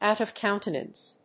アクセントòut of cóuntenance